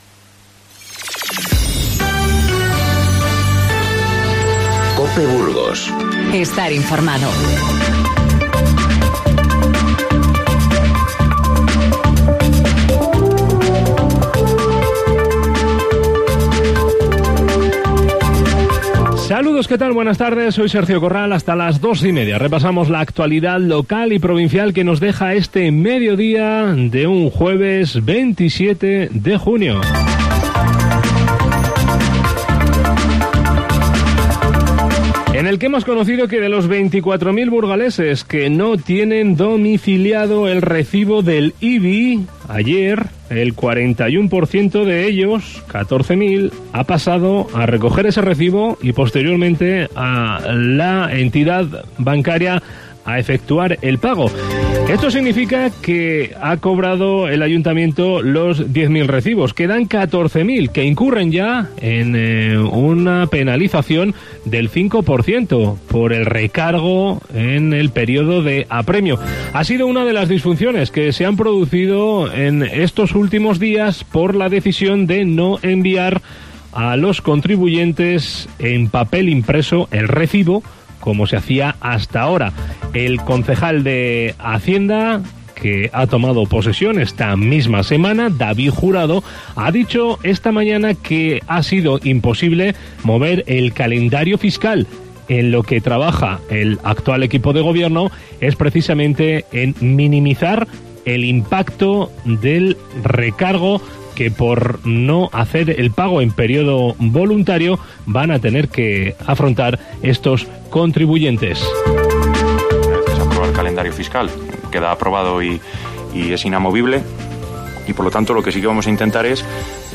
Informativo Mediodía COPE Burgos 27/06/19